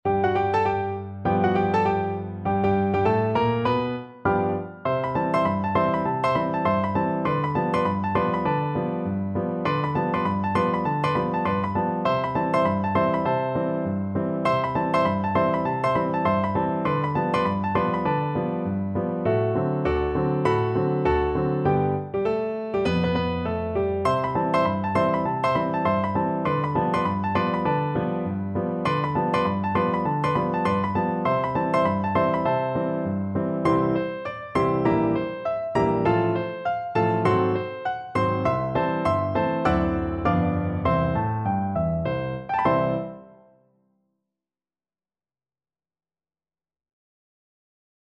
Free Sheet music for Piano Four Hands (Piano Duet)
Fast swing =c.200
Piano Duet  (View more Easy Piano Duet Music)
Jazz (View more Jazz Piano Duet Music)